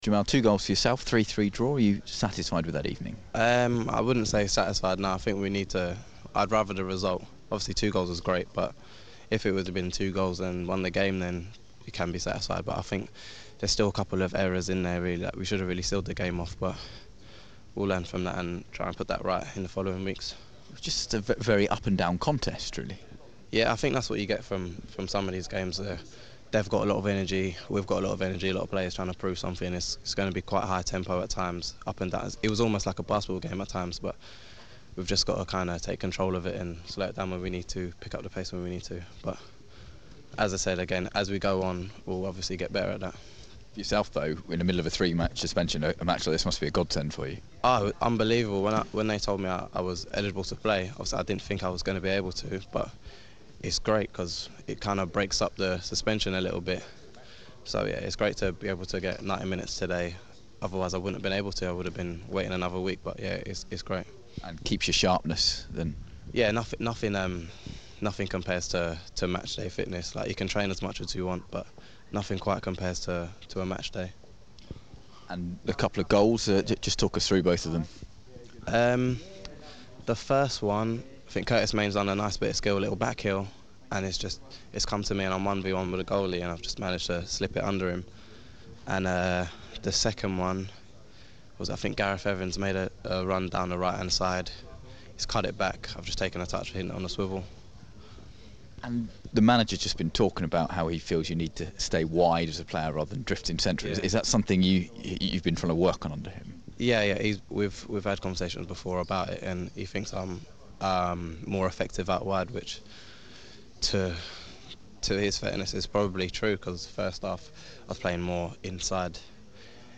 Jamal Lowe speaks after his 2 goals in a 3-3 draw with Fulham